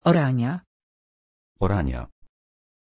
Pronunciation of Orania
pronunciation-orania-pl.mp3